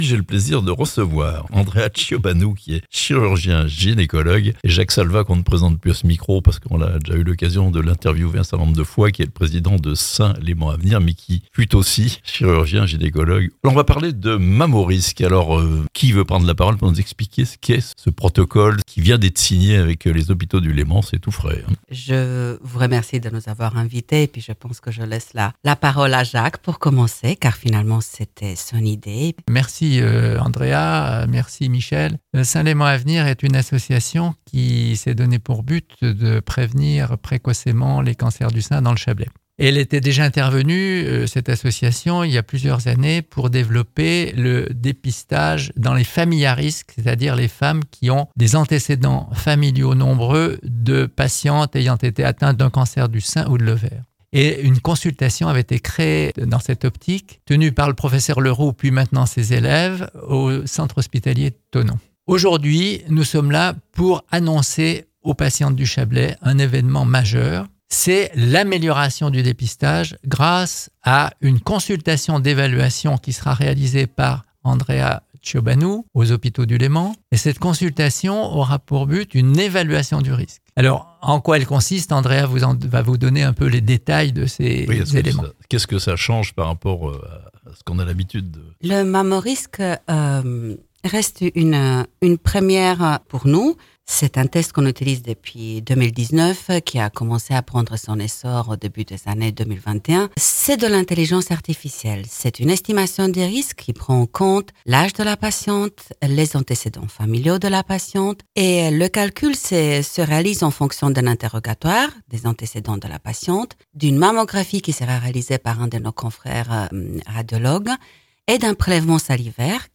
Une avancée majeure pour le dépistage du cancer du sein en Chablais (Interviews)